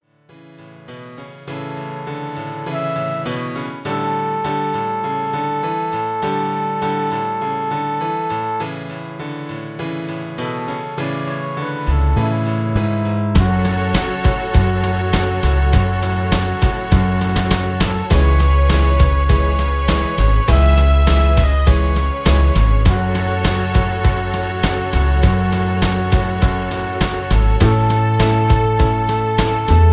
Karaoke MP3 Backing Tracks
Just Plain & Simply "GREAT MUSIC" (No Lyrics).
mp3 backing tracks